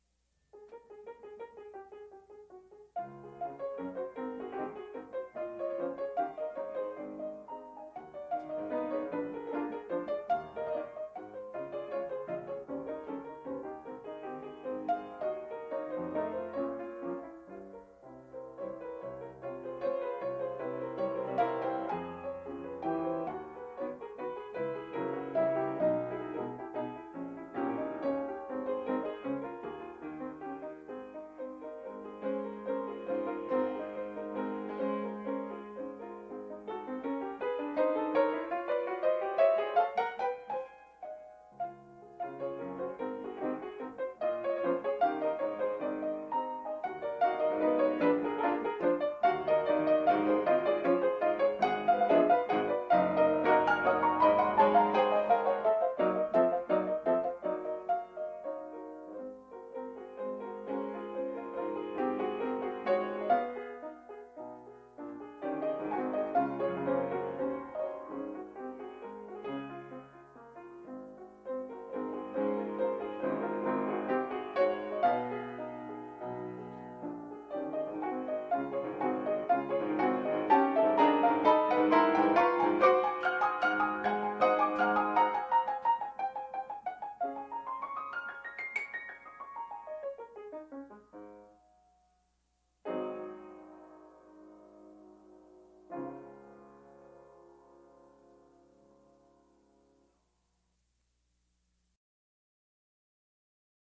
Piano  (View more Advanced Piano Music)
Classical (View more Classical Piano Music)